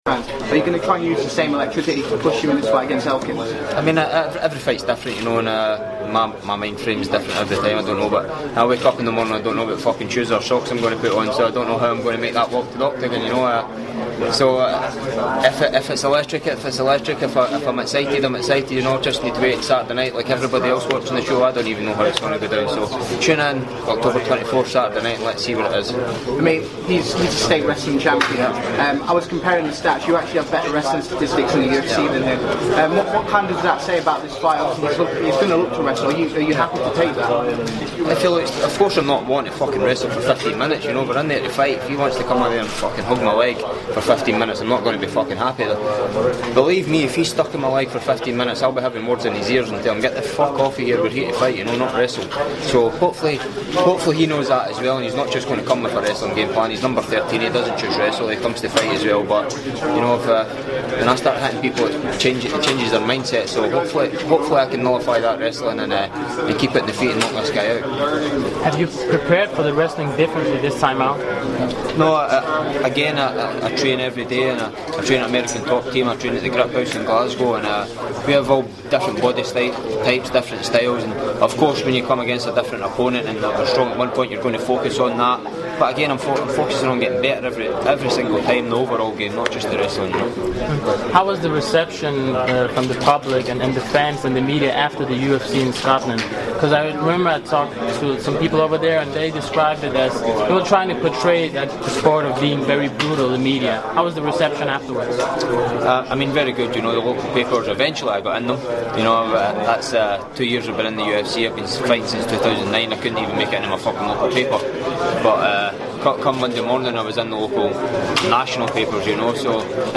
at the UFC Dublin media day